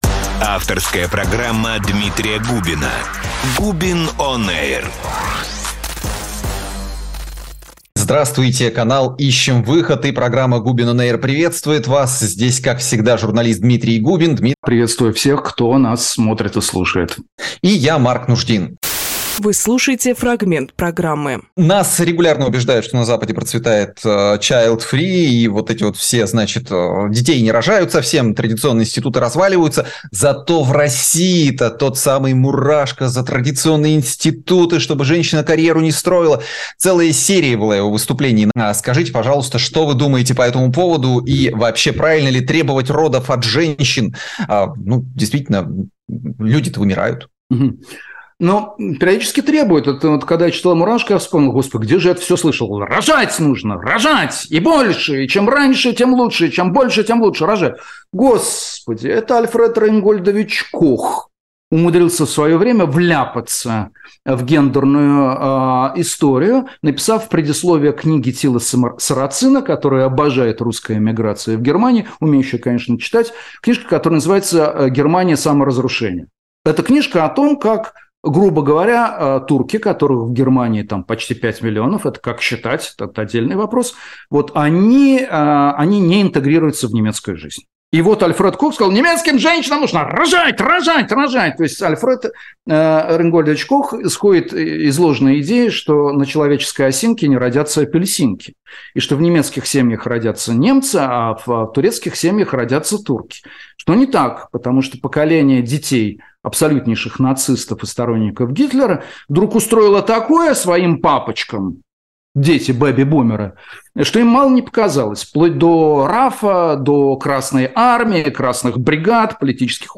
Дмитрий Губинжурналист
Фрагмент эфира от 21.07.23